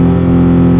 Seamless loop of engine sound
There’s always a slightly distinguishable “zip” sound at the looping point.
I tried to hold the engine at 5000 rpms for a few seconds so that the audio was at a constant amplitude.